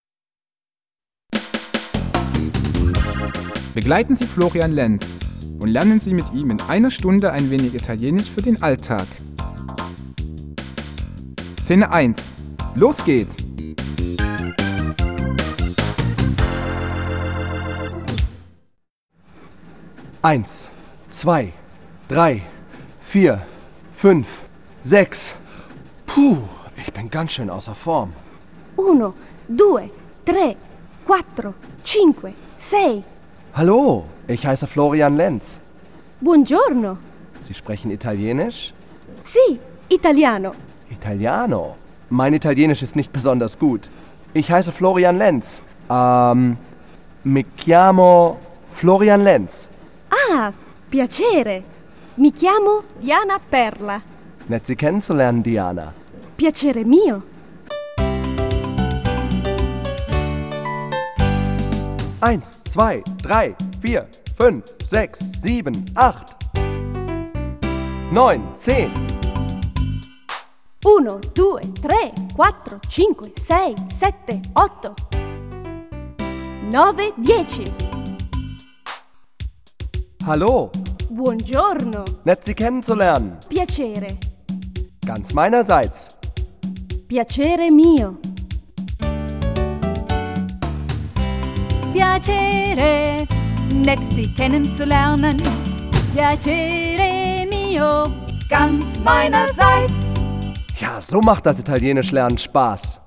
Ein musikalisches Sprachtraining